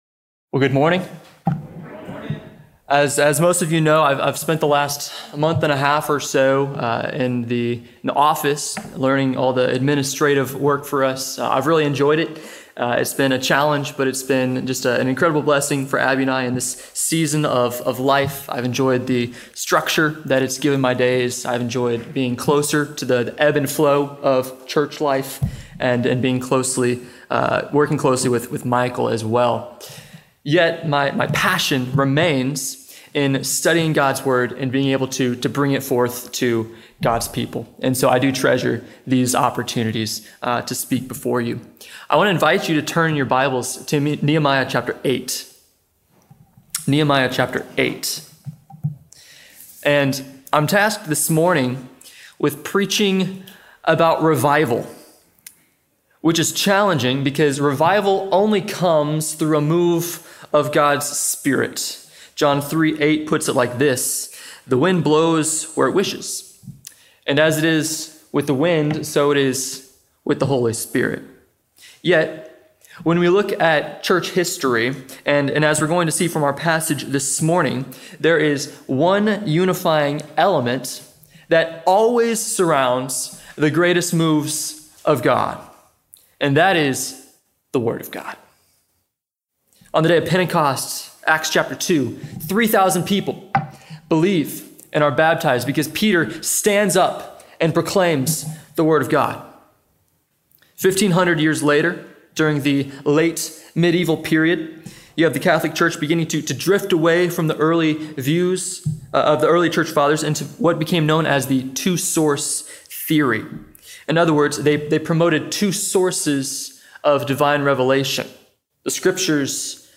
7-20-25-Sermon.mp3